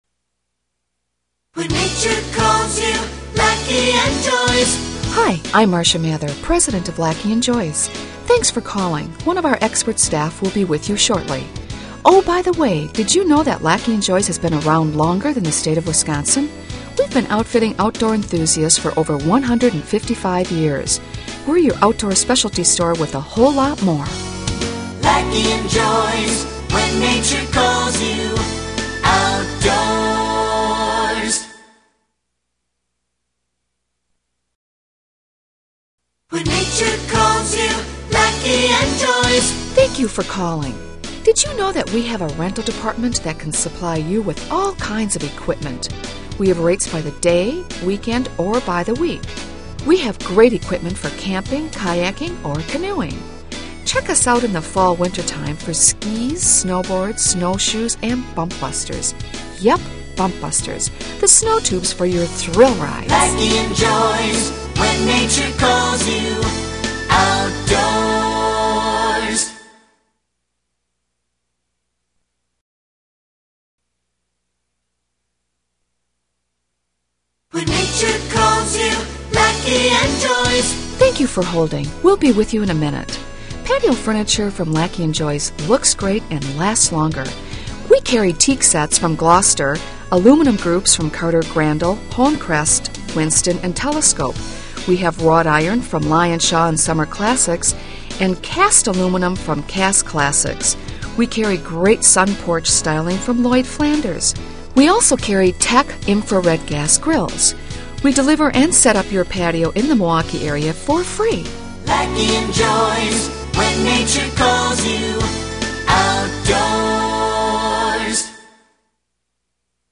Laacke & Joys On-Hold Messaging